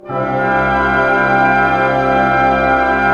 Index of /90_sSampleCDs/Roland LCDP08 Symphony Orchestra/ORC_ChordCluster/ORC_Pentatonic